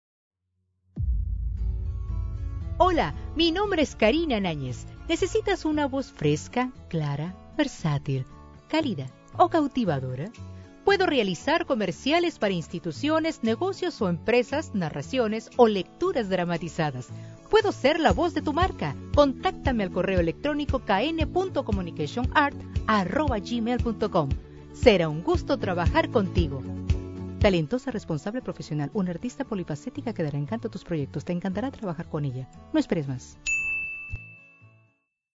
Demo Personal
Spanish - Neutral
Young Adult
Middle Aged